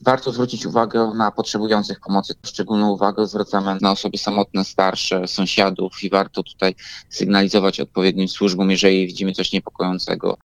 O zwracanie w tym czasie szczególnej uwagi na osoby bezdomne apelują policjanci i strażnicy miejscy. Ale, jak przypomina prezydent Ełku Tomasz Andrukiewicz, nie tylko one mogą potrzebować teraz uwagi i pomocy.
Tomasz-Andrukiewicz.mp3